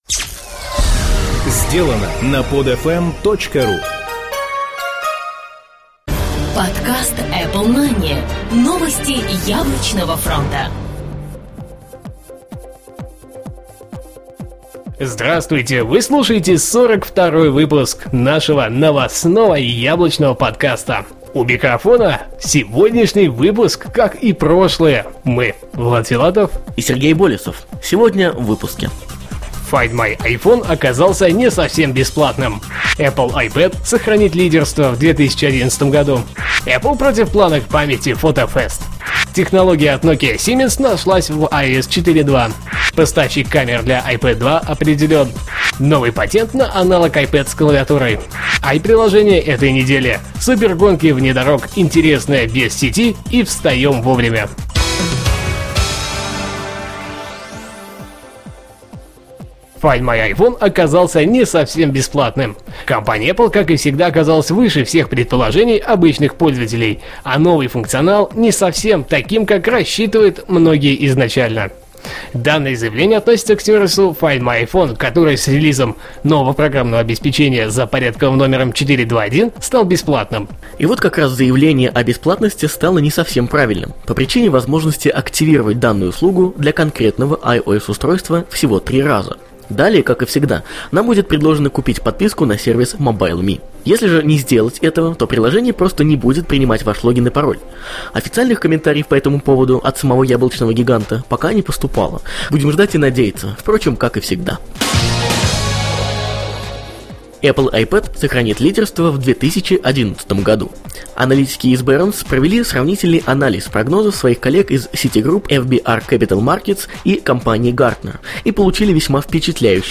"Apple Mania" - еженедельный новостной Apple подкаст
Жанр: новостной Apple-podcast